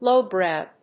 音節lów・bròw 発音記号・読み方
/‐brὰʊ(米国英語)/